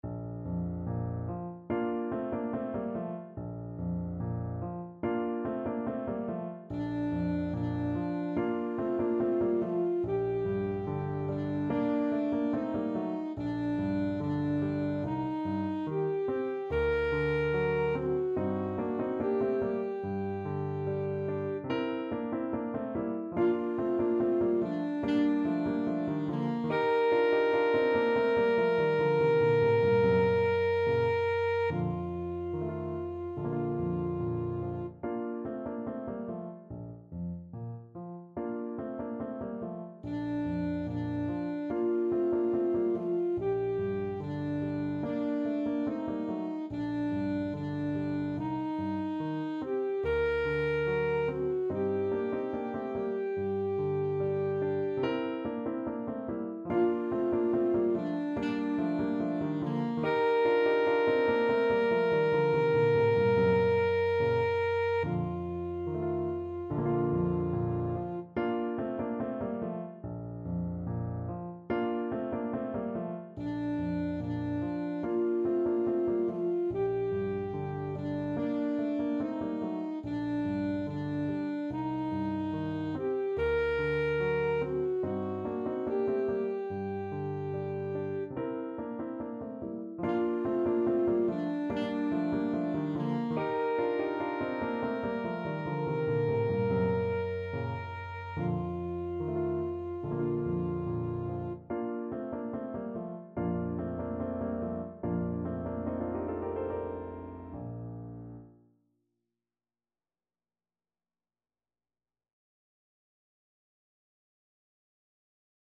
Classical
Time Signature: 4/4
Score Key: Bb major (Sounding Pitch)
Tempo Marking: Allegretto ( =72)